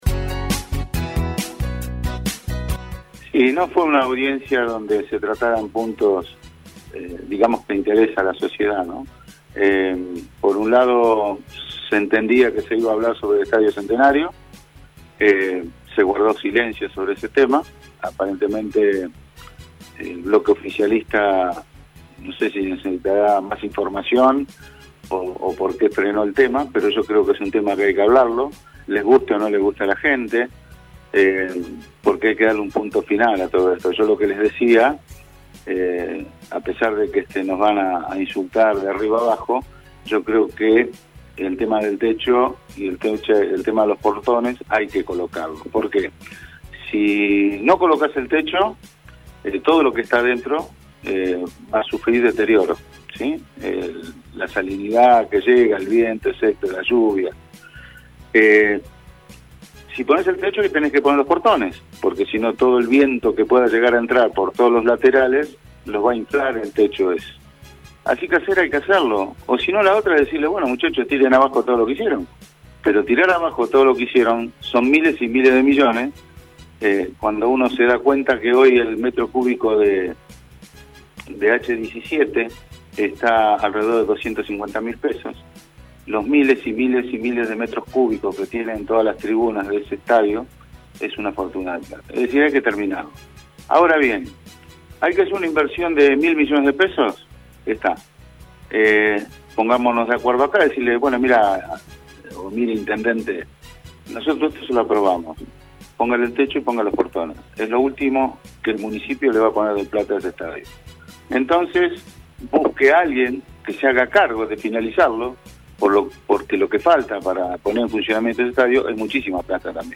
El concejal del Plich, Omar Lattanzio, comentó ante los micrófonos de RADIOVISIÓN lo que fue ayer su hora de preferencia en la sesión ordinaria del Concejo Deliberante, el estadio del centenario fue el tema que trató y esperaba que otros ediles se sumaran, lo que no sucedió: